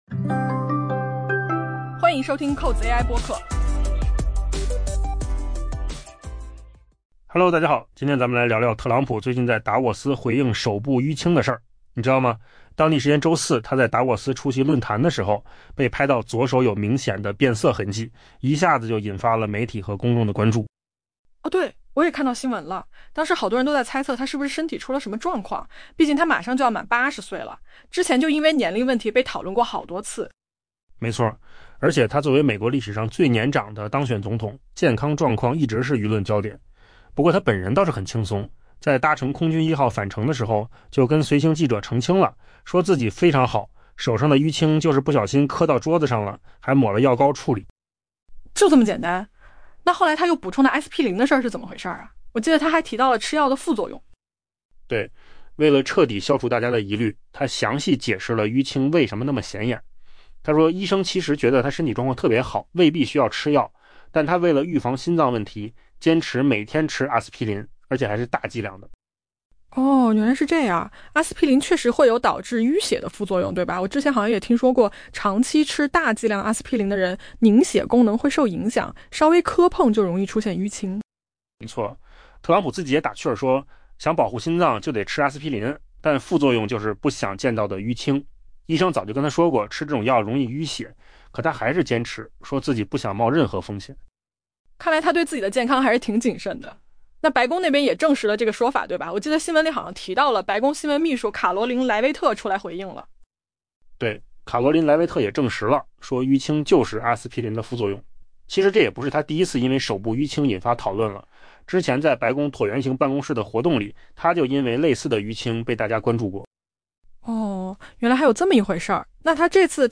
AI播客：换个方式听新闻 下载mp3 音频由扣子空间生成 当地时间周四，美国总统特朗普在瑞士达沃斯出席世界经济论坛期间，针对外界对其手部明显淤青的猜测进行了正面回应。